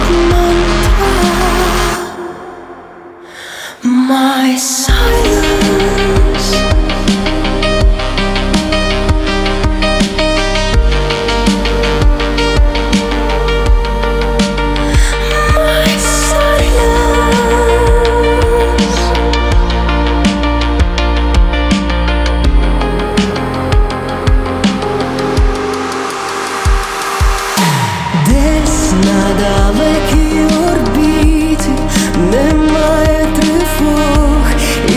Жанр: Электроника / Классика
Electronic, Classical, Classical Crossover